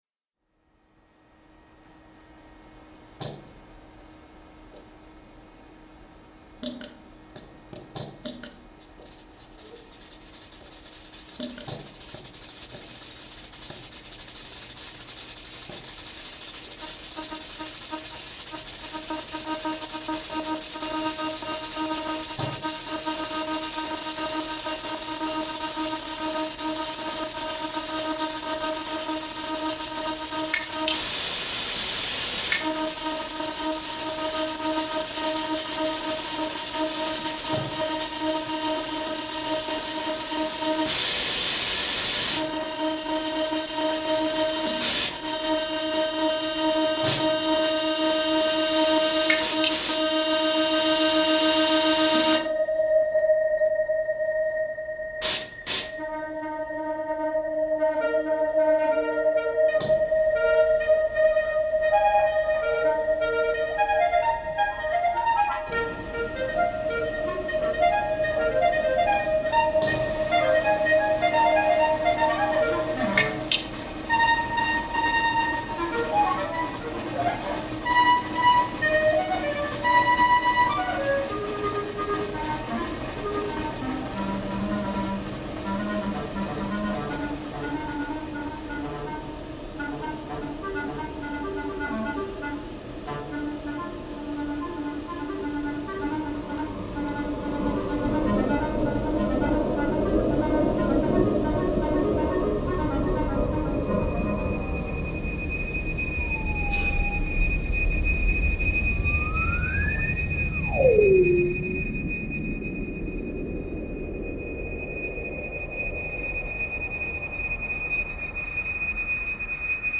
Solo Clarinet
for Bb clarinet and fixed media